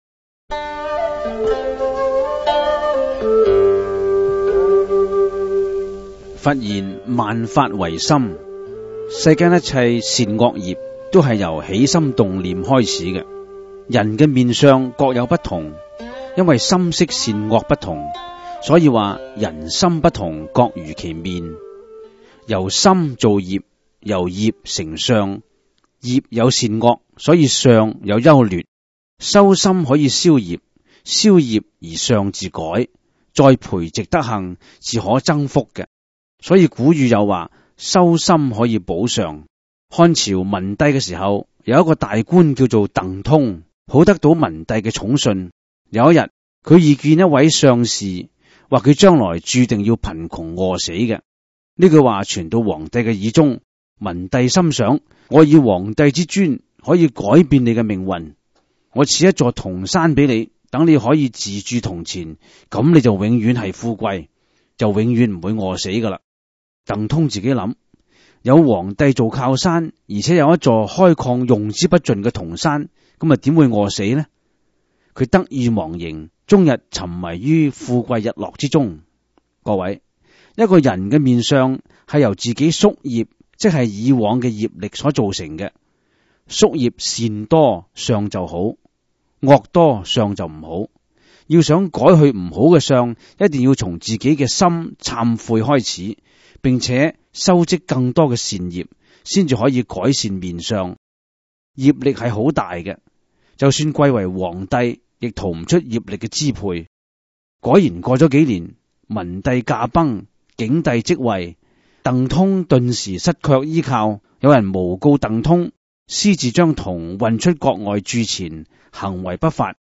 第 十 五 辑       (粤语主讲  MP3 格式)